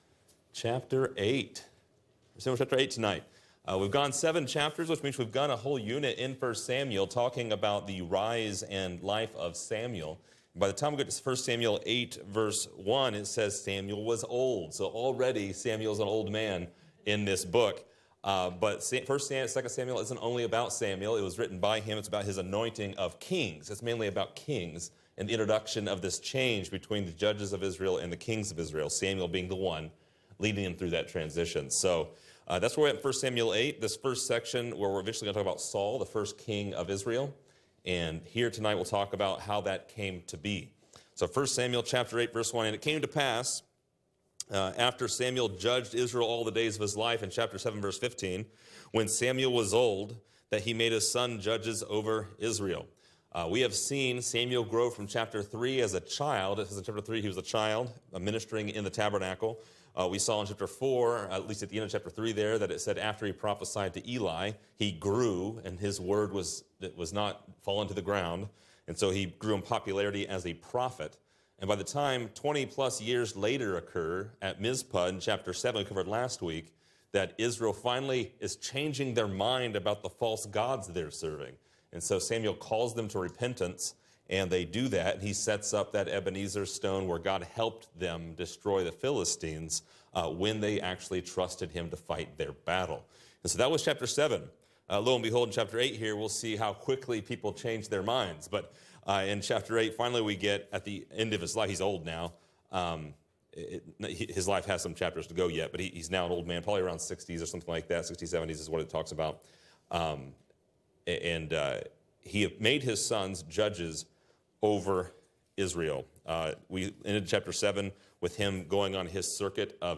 Description: This lesson is part 9 in a verse by verse study through 1 Samuel titled: Give Us a King